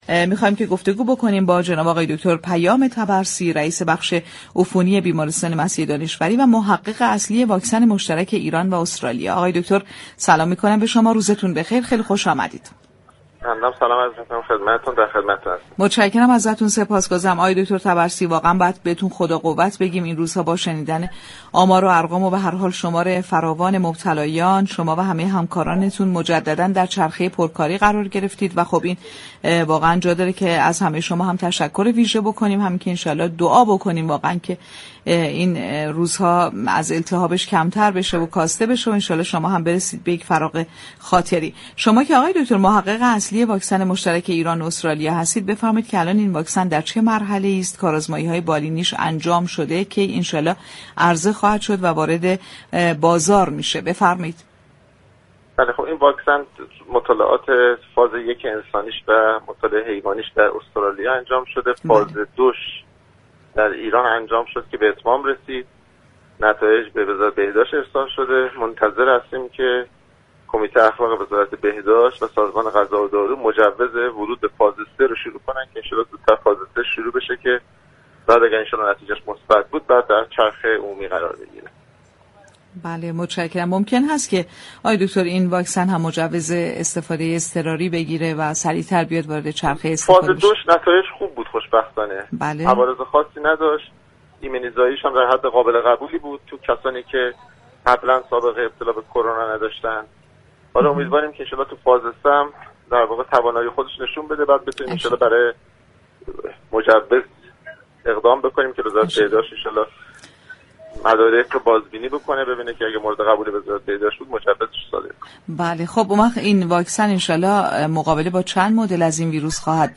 در گفتگو با برنامه تهران ما سلامت